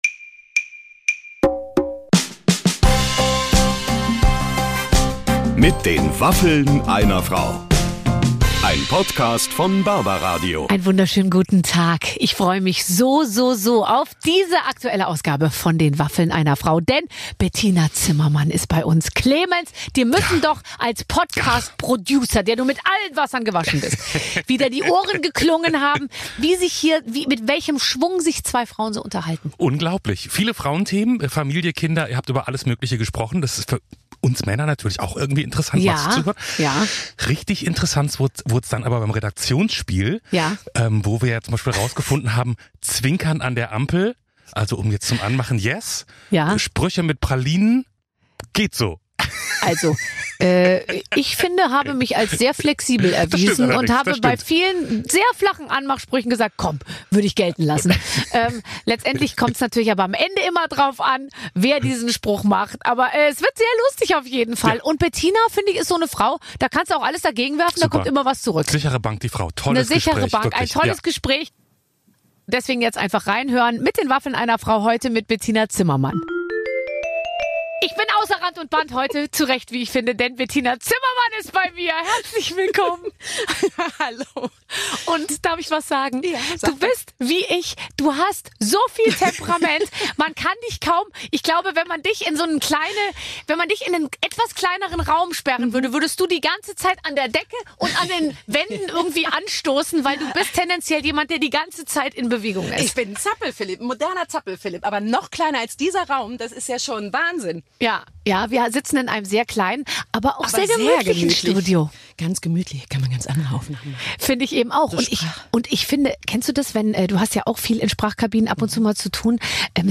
Schauspielerin Bettina Zimmermann hat sich in unseren Podcast verirrt! Es wird viel gelacht und getratscht - unter anderem über Bettinas High-Heel-Füße, den sogenannten Mami-Sport und ihre Liebe zum Baggerfahren.